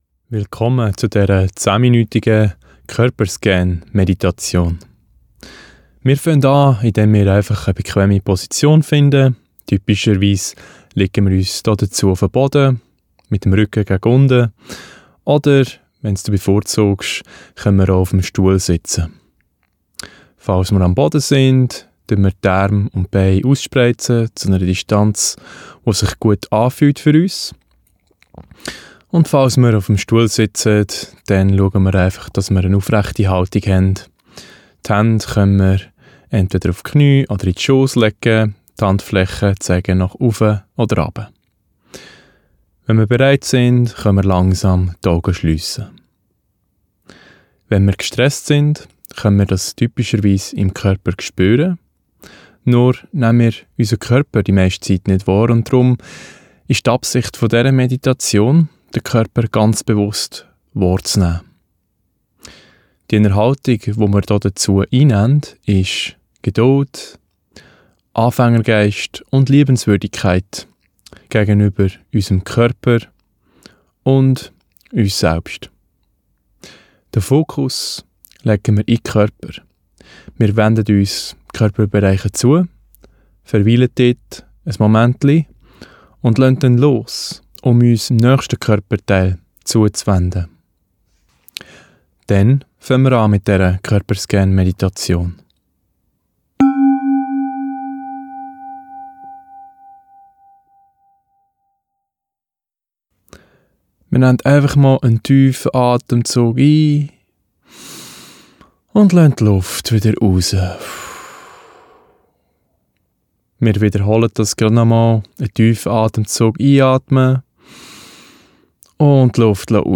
10m Körperscan Meditation (Schwiizerdütsch)
10m-Korperscan-Meditation-MBSR.mp3